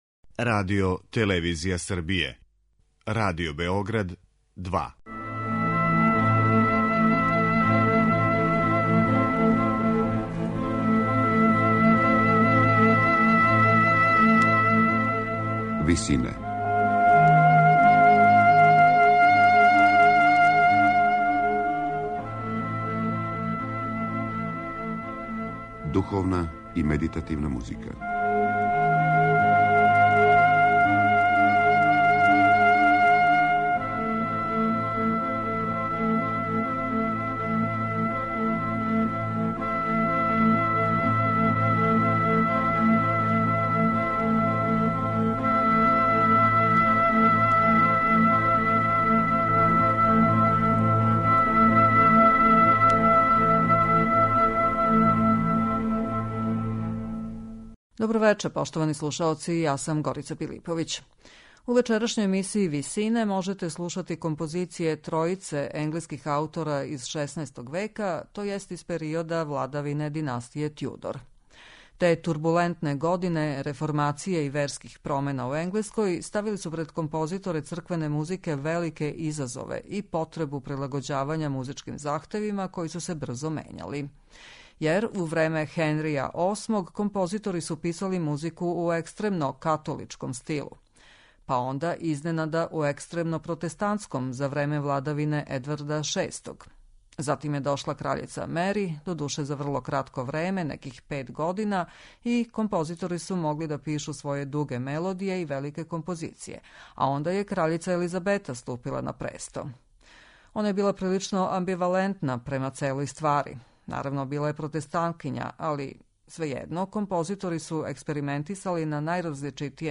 Духовна музика у доба Тјудора
У емисији Висине можете слушати духовне композиције енглеских аутора из XVI века, тј. из периода владавине династије Тјудор.